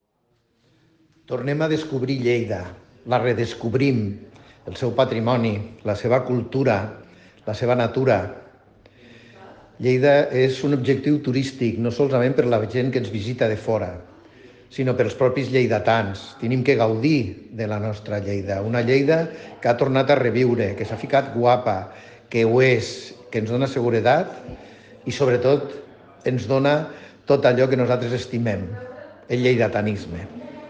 tall-de-veu-del-tinent-dalcalde-paco-cerda-sobre-les-propostes-de-promocio-turistica-a-lleida-pel-pont-del-pilar